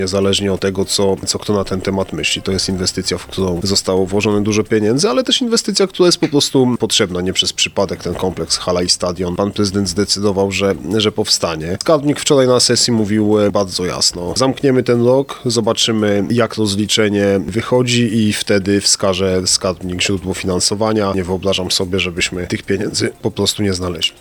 Tym samym nie wiadomo czy ta inwestycja zostanie dokończona, choć jak podkreślił w Mocnej Rozmowie wiceprezydent Mateusz Tyczyński koniec budowy stadionu jest priorytetem.